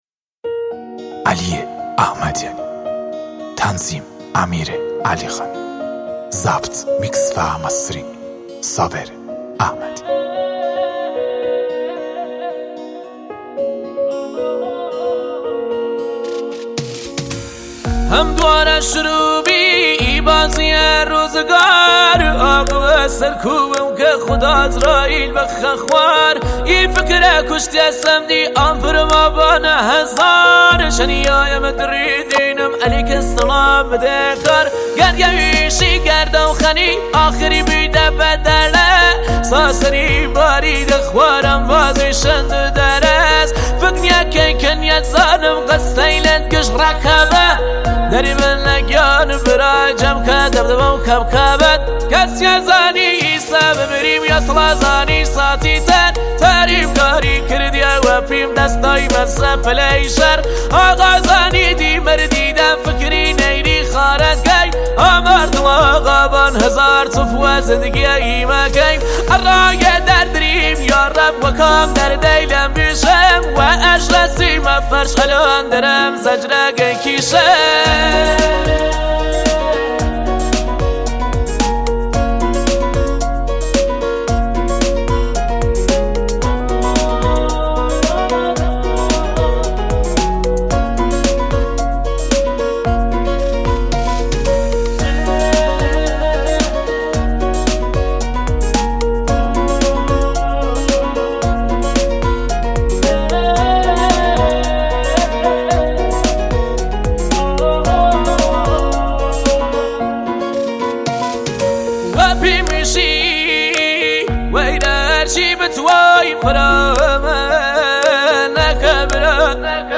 آهنگ کردی